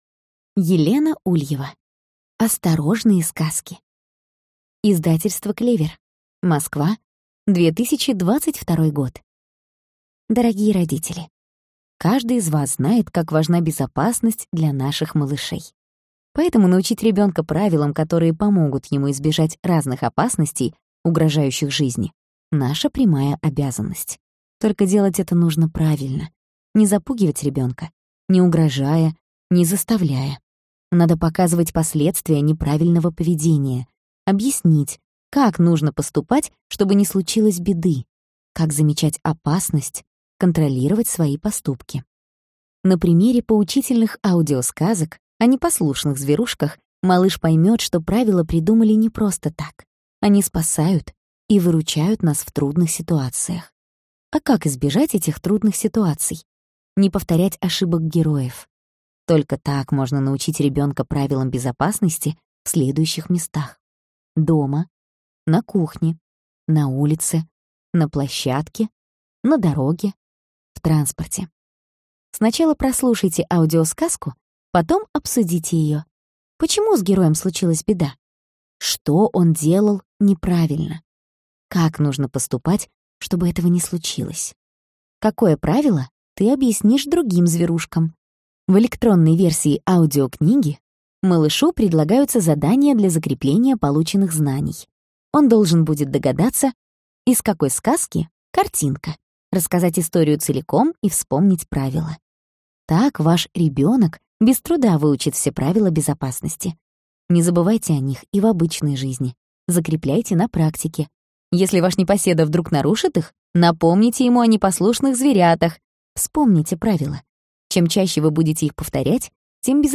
Аудиокнига Осторожные сказки. Правила безопасности | Библиотека аудиокниг